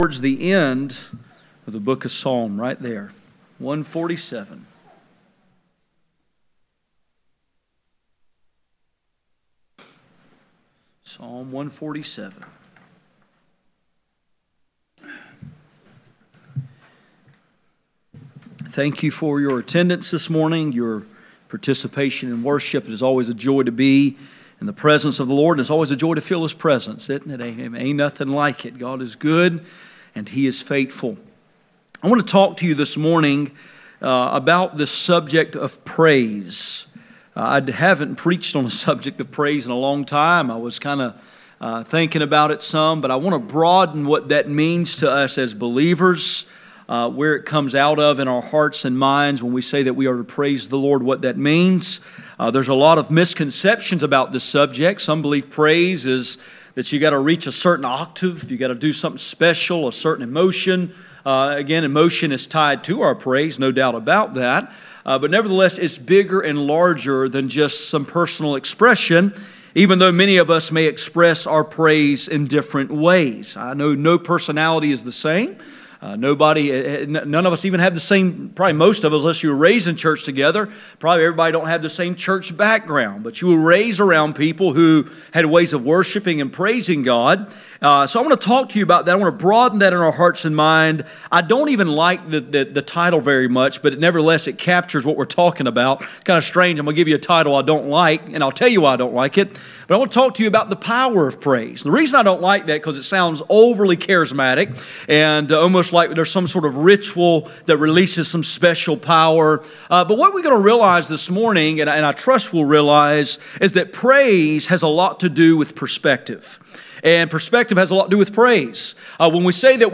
Psalm 147:1-Philemon 147:20 Service Type: Sunday Morning %todo_render% « Persecution